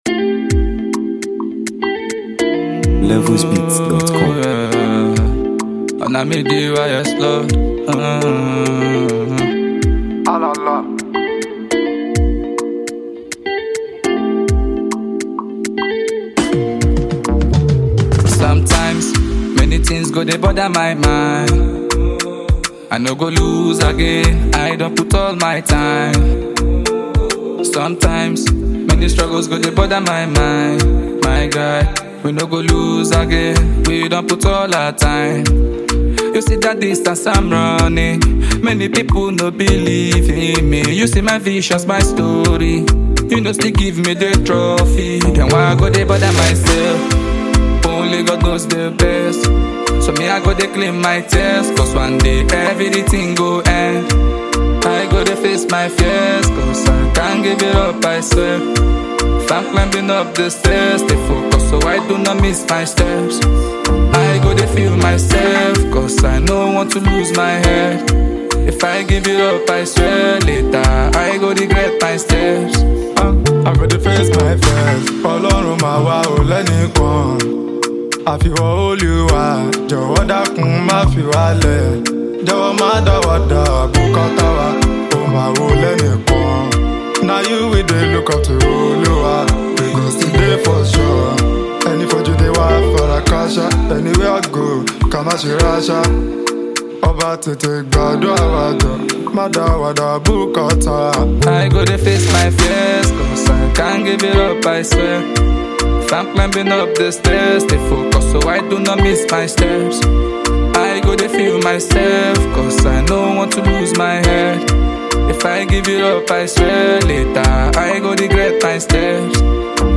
soothing yet inspiring soundscape
smooth production, and inspiring vibe